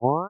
wah.ogg